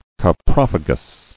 [cop·roph·a·gous]